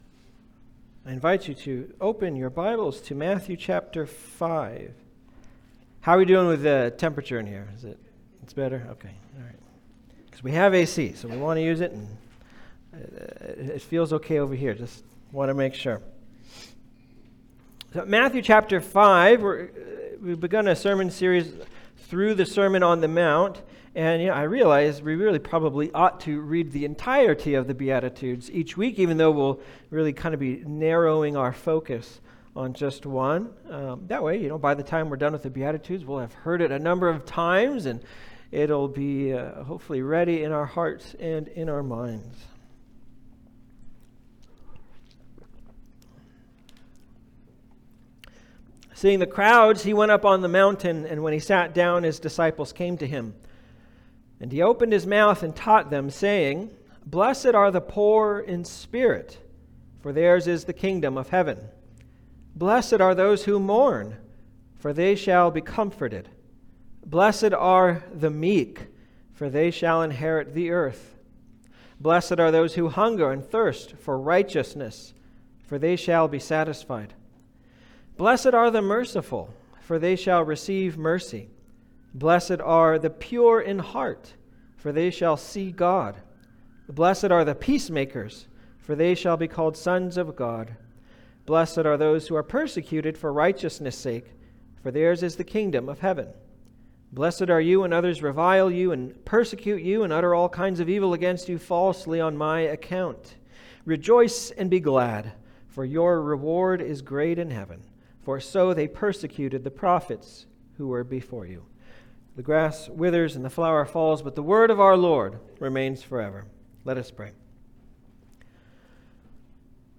Matthew 5:5 Service Type: Special Service « David’s Miktams Hungering and Thirsting for What?